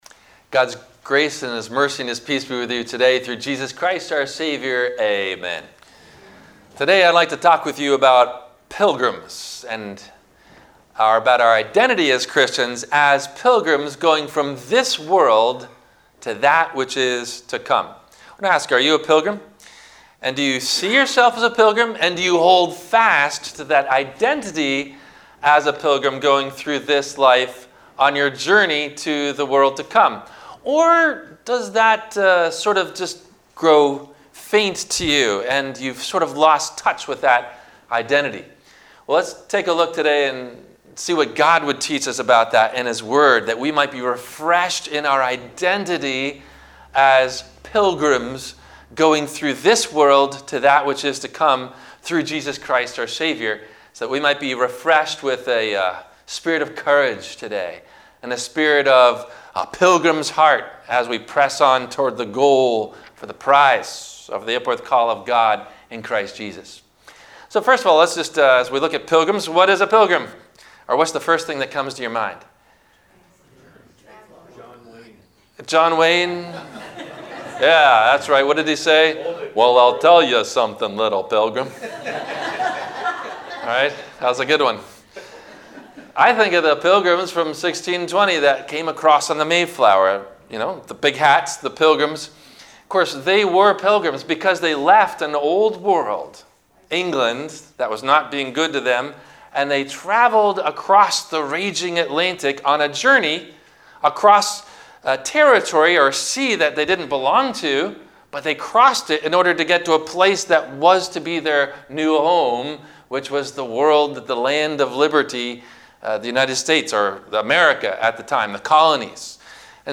- Sermon - March 21 2021 - Christ Lutheran Cape Canaveral